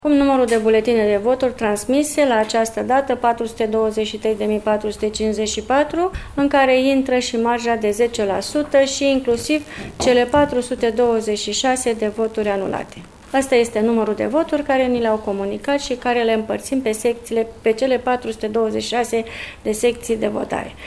Prefectul Carmen Ichim a prezentat principalele coordonate ale organizării alegerilor la nivelul judeţului nostru:
Prefecta.mp3